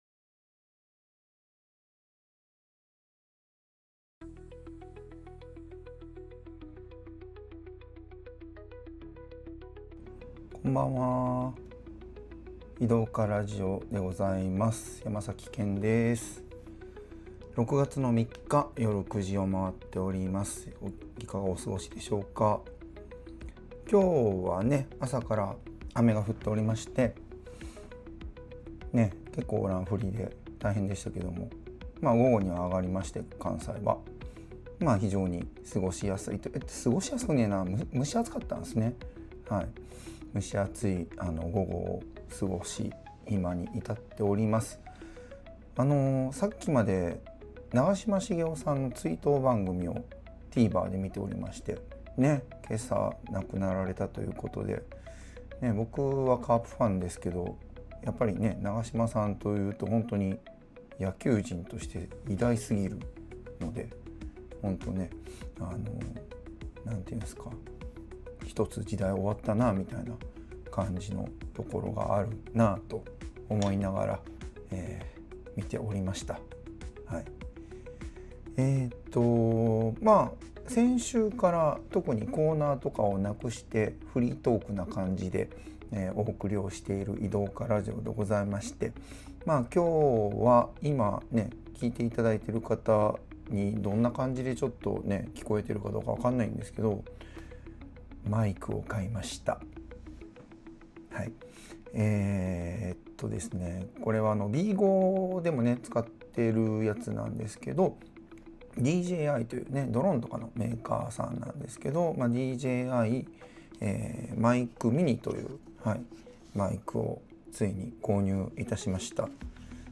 今回もフリートークのみでおとどけしました。